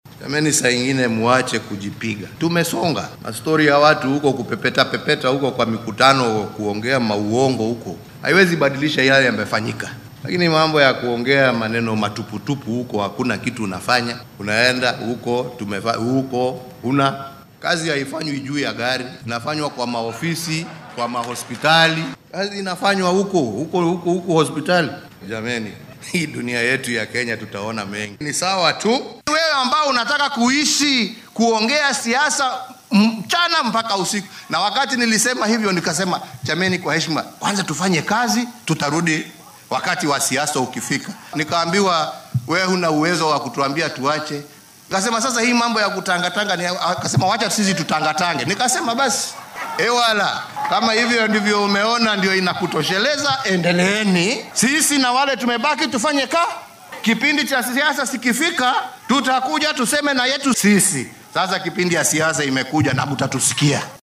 Madaxweynaha ayaa arrimahan ka hadlay xilli uu si rasmi ah u furayay shir sanadeedka shaqaalaha caafimaadka ee sanadkan, waxaa uuna sheegay in Kenya ay soo saarayso shaqaale caafimaad oo tayo leh.